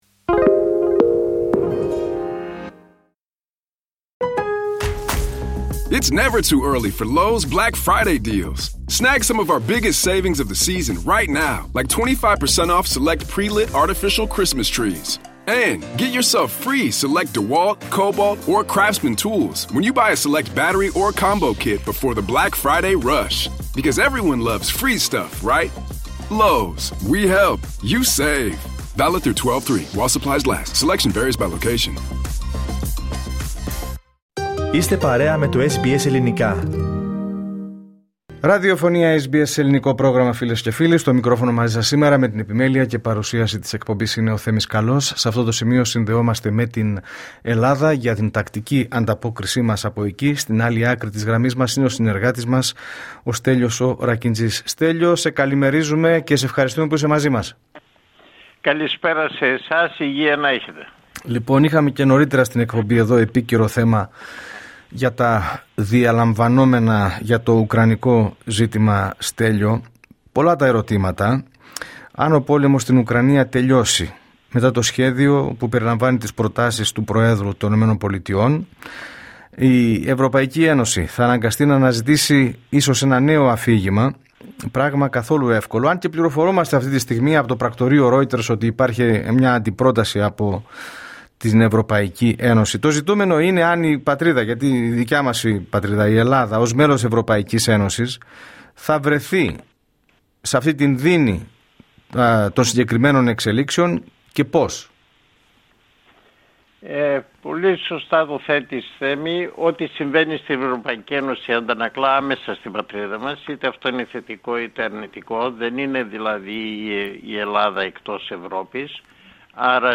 Ακούστε την εβδομαδιαία ανταπόκριση από Ελλάδα